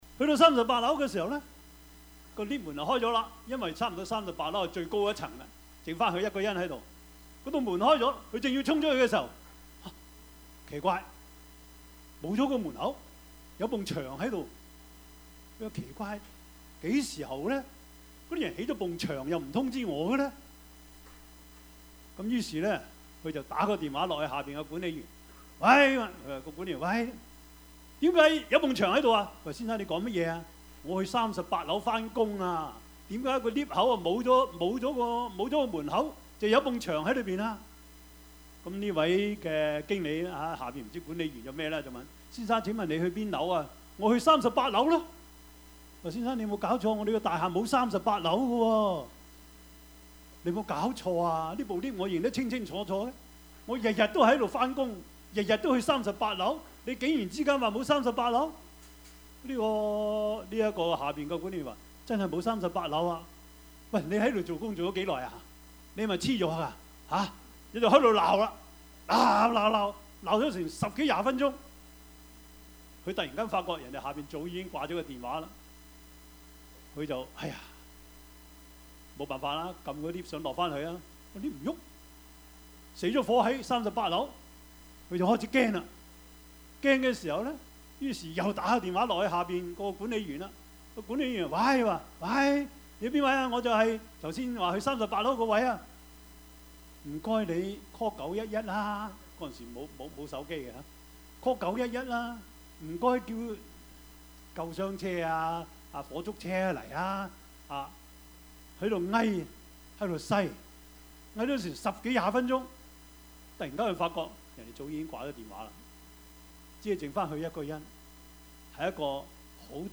Series: 2017 主日崇拜